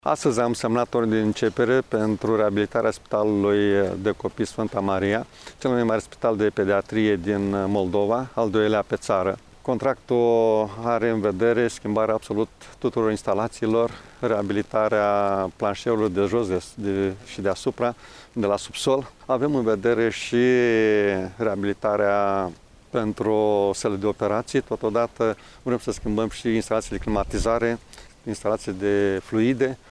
Preşedintele CJ Iaşi, Maricel Popa: